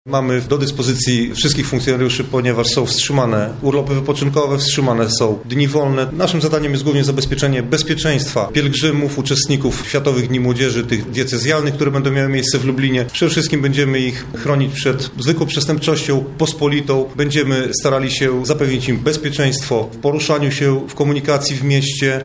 – Policja zabezpieczy lubelskie Światowe Dni Młodzieży pełnymi siłami – mówi inspektor Dariusz Dudzik, komendant miejski policji